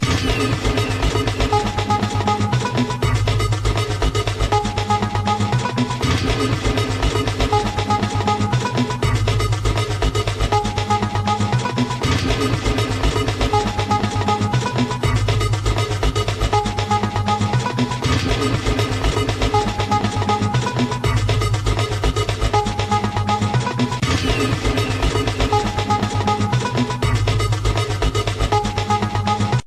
- le son : restitution de la stéréo très prochainement